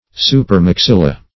Search Result for " supermaxilla" : The Collaborative International Dictionary of English v.0.48: Supermaxilla \Su`per*max*il"la\, n. [NL.